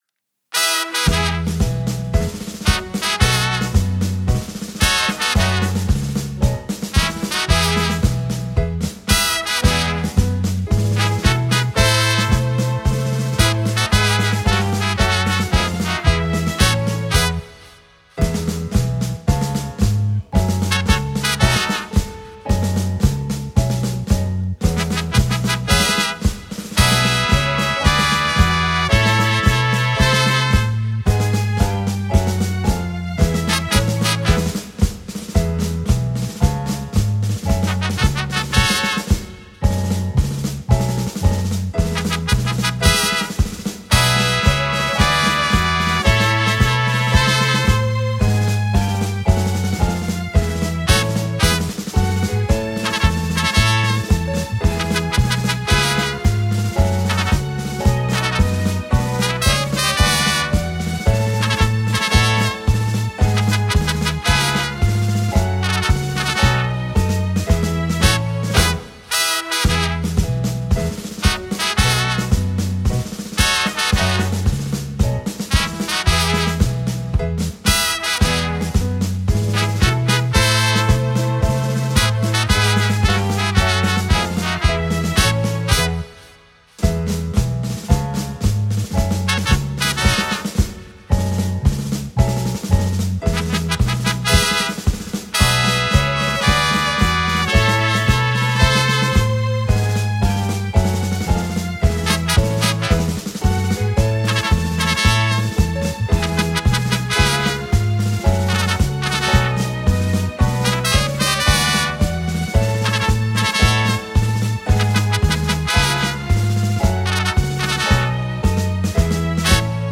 7.2 HIMNO DEL DEL GRUPO HONORÍFICO PANUPALI (INSTRUMENTAL)
himno-del-ghp-41-instrumental-ok.mp3